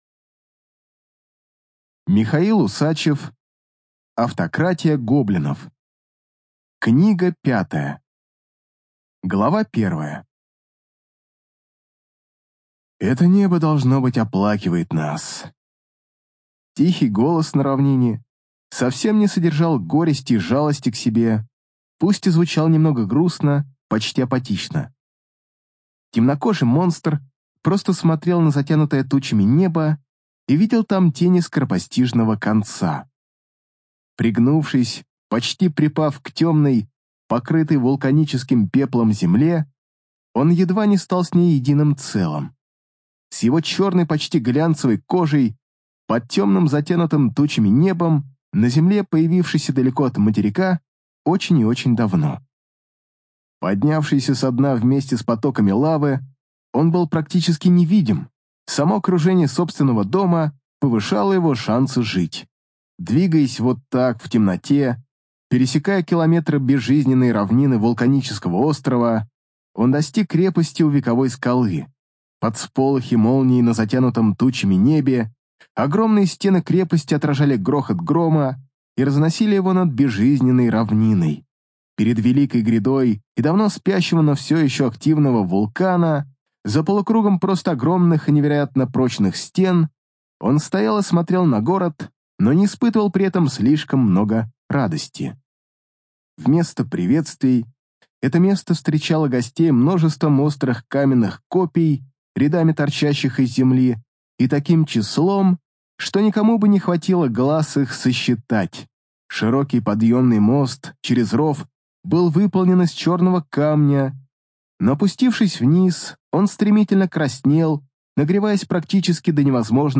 ЖанрЮмор и сатира, Фэнтези